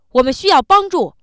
angry